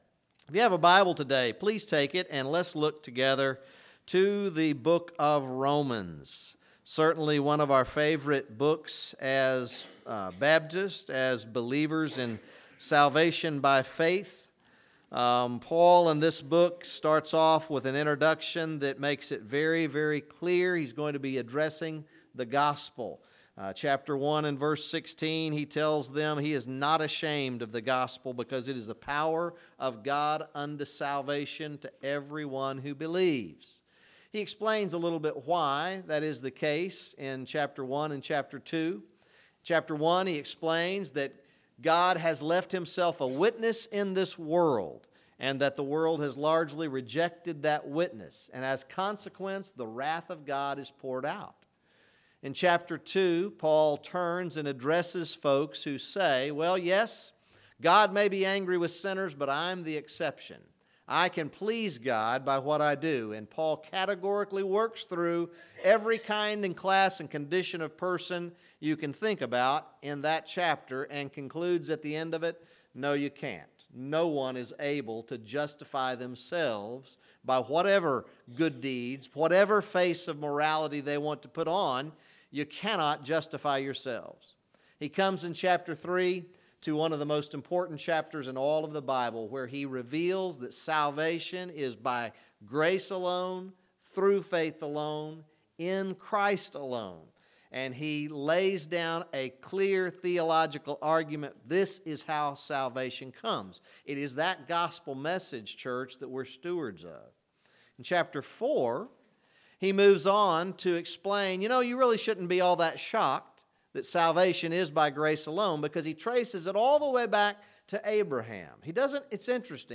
This Sunday evening sermon was recorded on June 9, 2019.